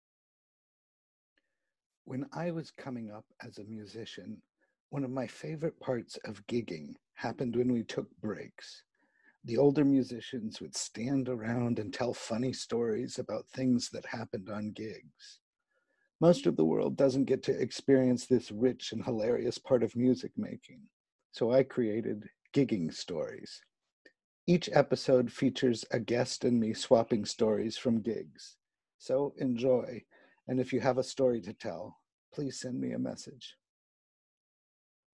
Each episode features a guest and me swapping stories from gigs.